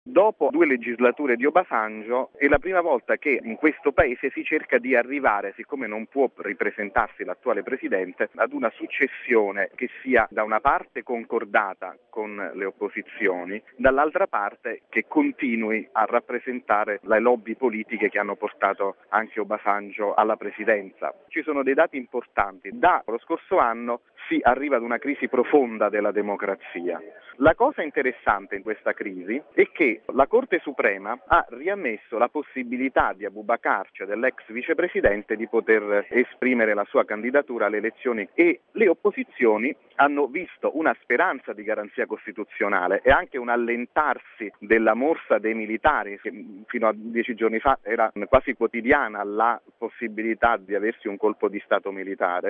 ha intervistato